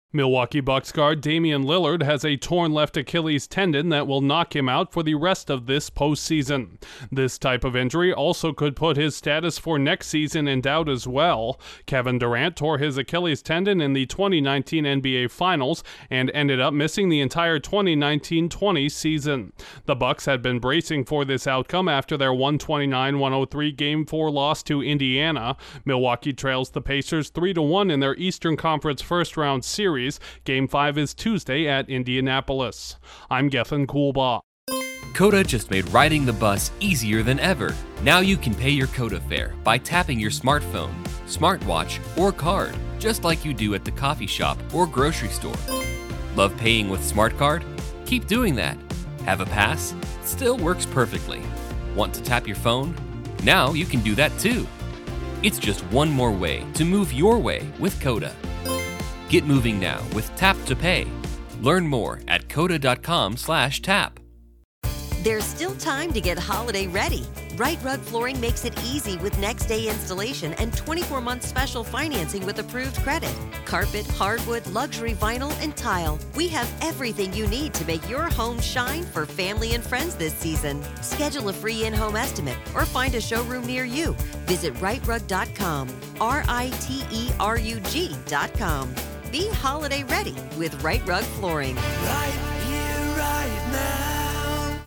The Bucks will be without one of their best players for the rest of the playoffs. Correspondent